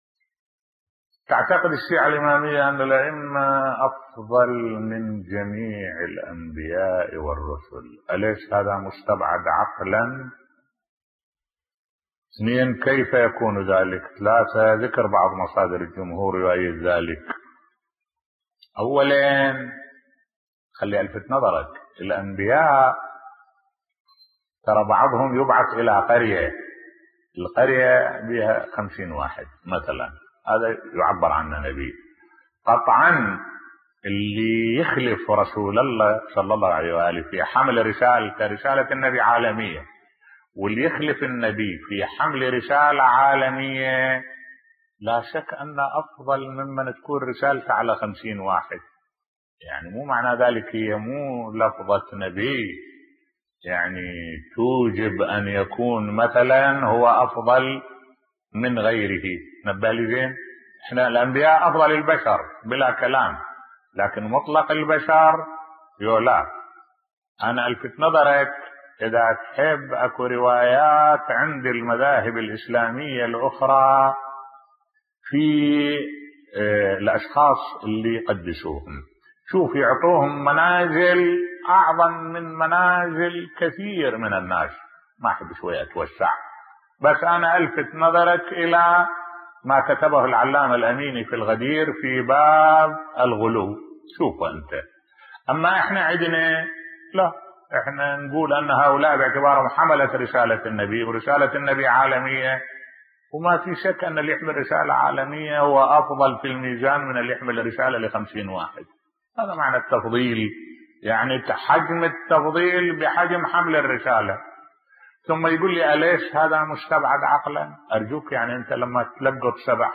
ملف صوتی سؤال وجواب - 2 بصوت الشيخ الدكتور أحمد الوائلي